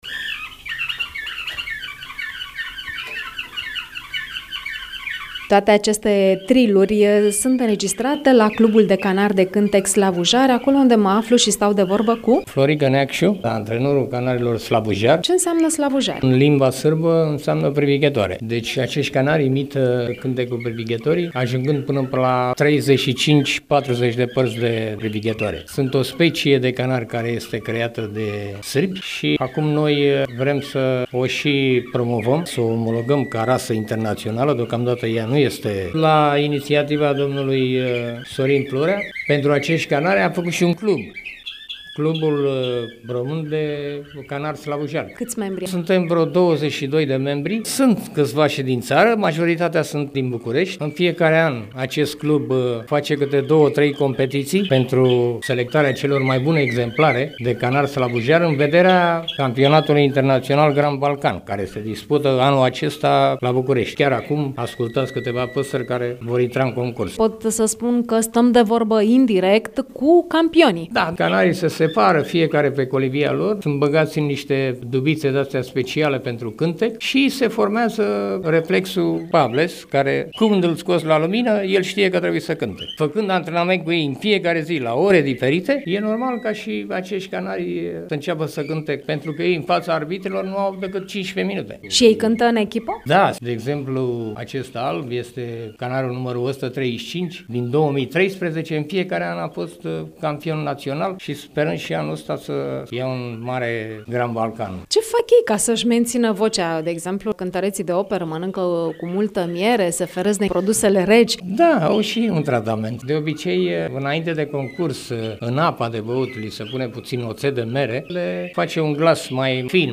interviu.mp3